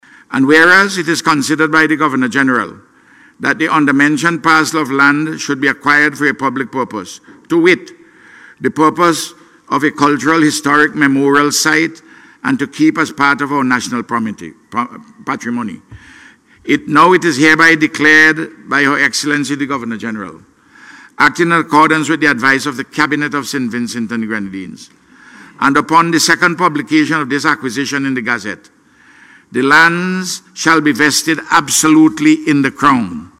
Prime Minister Dr Ralph Gonsalves made the announcement in Parliament today.